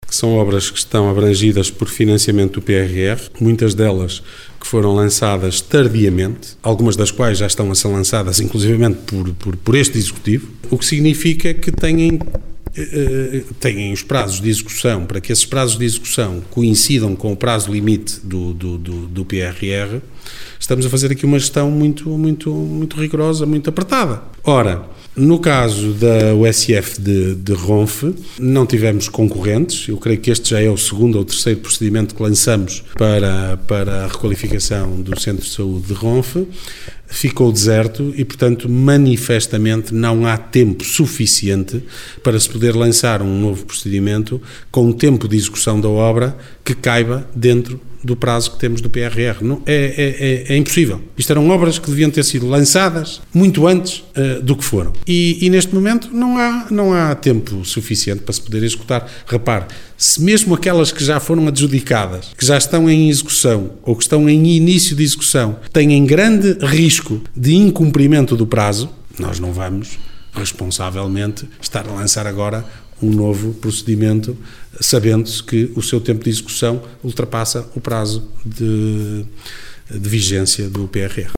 Declarações de Ricardo Araújo, presidente da Câmara Municipal de Guimarães. Falava esta semana aos jornalistas, no final da reunião do executivo municipal.